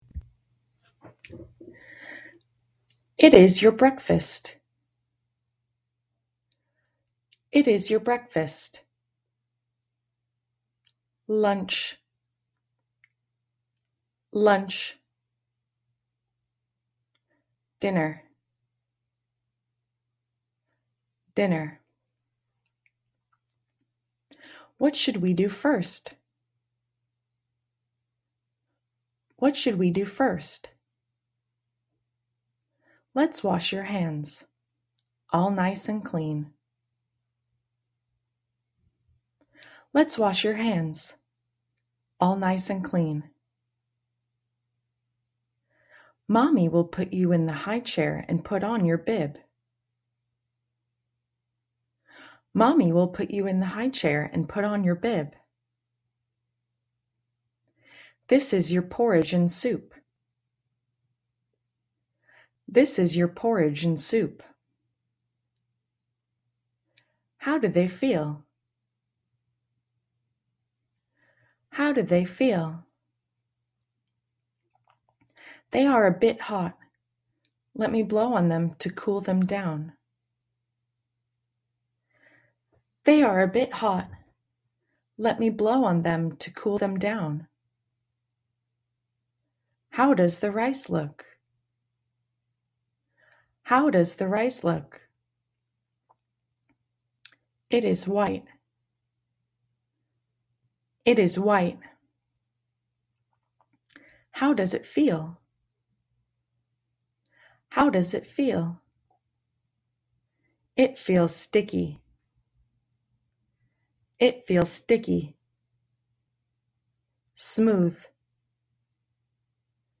Mẫu hội thoại xoay quanh cuộc sống hằng ngày của con, được ghi âm trực tiếp từ người Mỹ không những giúp phụ huynh ôn tập mà còn giúp luyện nói, phát âm và nghe.